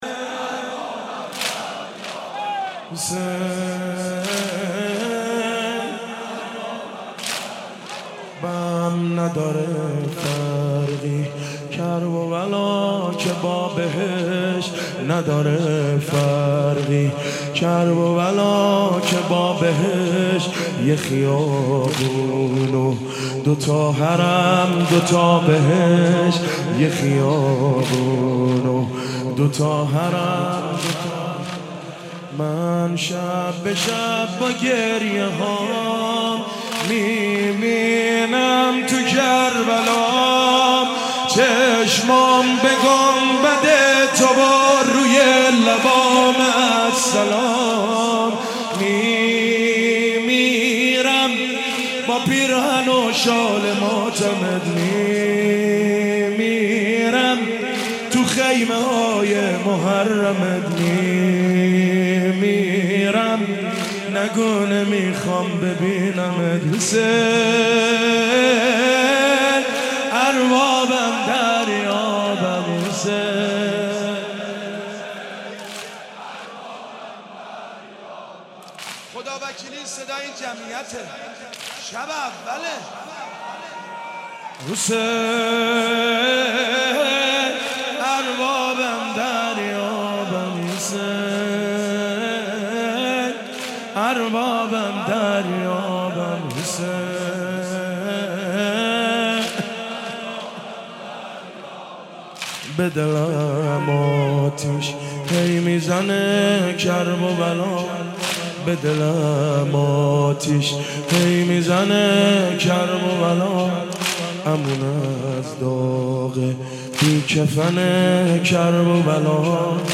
واحد مداحی